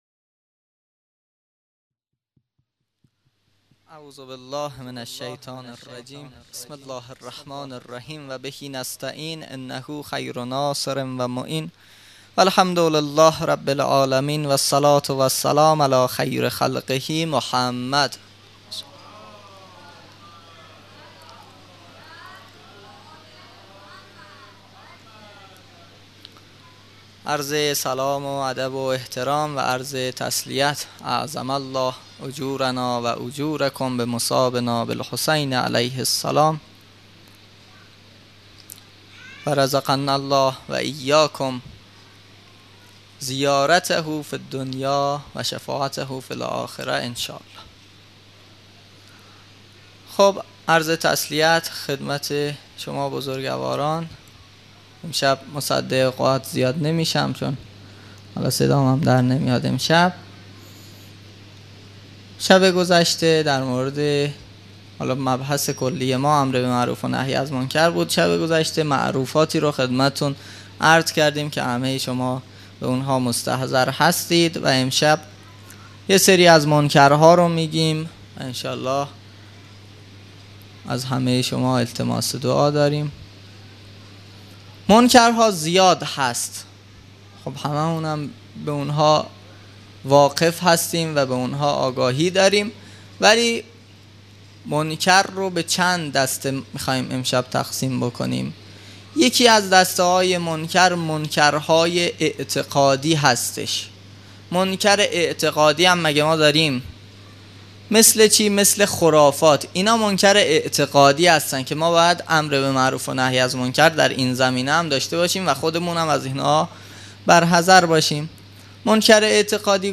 مراسم عزاداری محرم الحرام ۱۴۴۳_شب یازدهم